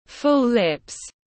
Môi dày đầy đặn tiếng anh gọi là full lips, phiên âm tiếng anh đọc là /fʊl lɪp/ .
Để đọc đúng từ môi dày đầy đặn trong tiếng anh rất đơn giản, các bạn chỉ cần nghe phát âm chuẩn của từ full lips rồi nói theo là đọc được ngay.